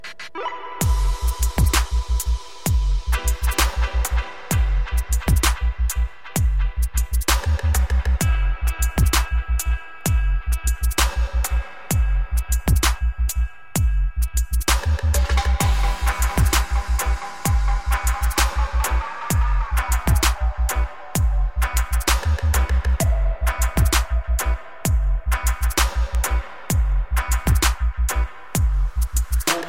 TOP >Vinyl >Grime/Dub-Step/HipHop/Juke